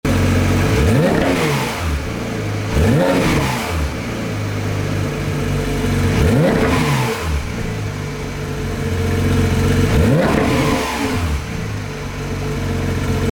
Listen to the sweet symphony of 9,000RPM
• RS Titanium Race Catback Exhaust System with exhaust valve noise control (Titanium exhaust was exclusive for the 2018 GT3 RS, post-2018 cars had steel exhaust systems)
GX18-FBV-GT3-RS-Sound-Clip.mp3